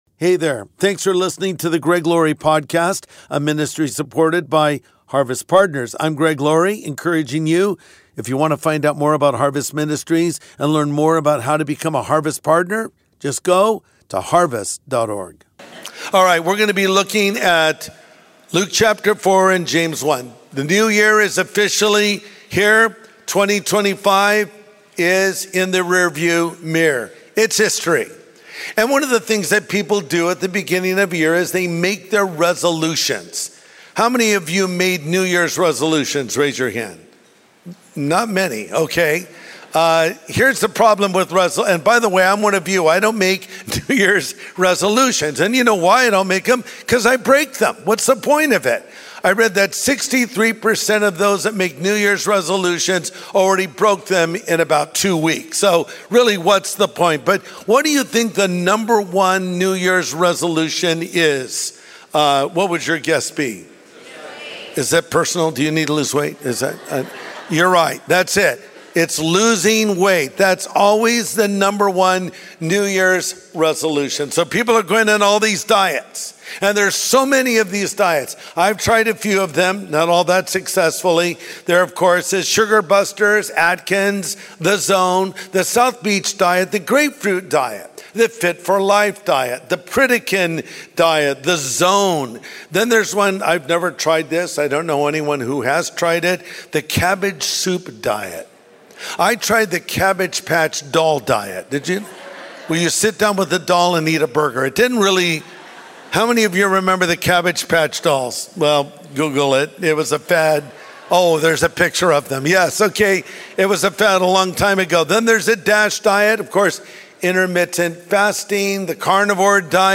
When Temptation Comes Knocking | Sunday Message